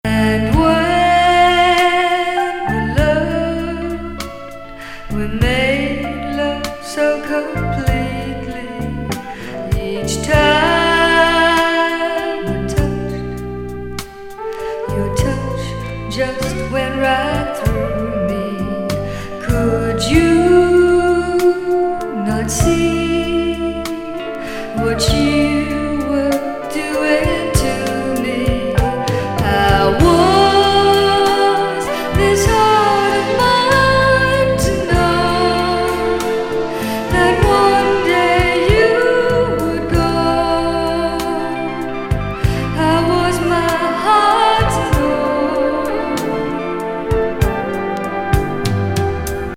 女性バラード!